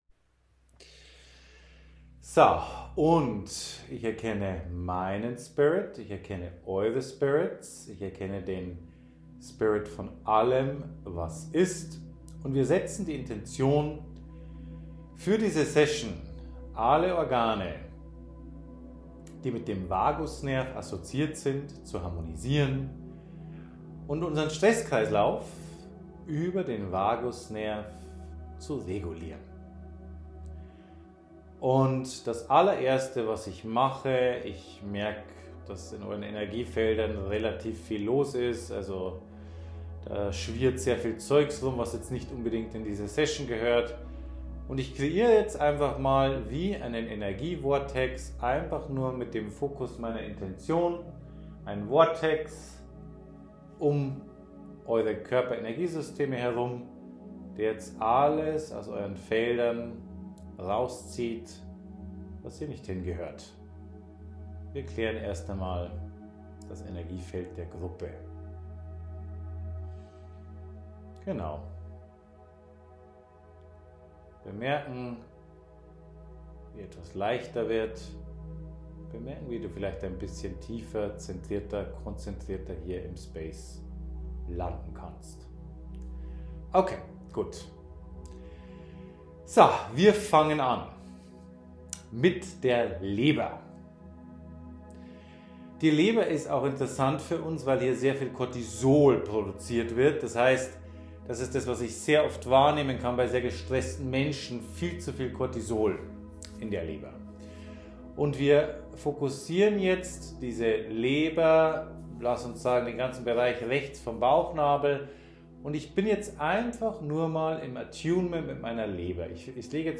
In dieser Session, die ich im Rahmen einer Ausbildung gegeben habe, detoxen wir alle Organe des Vagus-Systems, bringen sie zurück in ihre Ursprungsfrequenz und generieren damit einen totalen Frequenz-Reboot für den Vagusnerv.